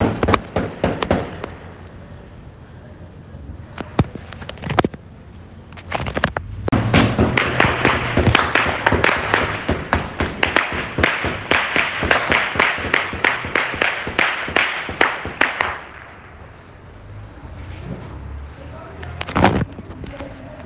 balazos.mp3